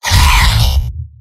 Giant Robot lines from MvM.
{{AudioTF2}} Category:Heavy Robot audio responses You cannot overwrite this file.
Heavy_mvm_m_painsharp02.mp3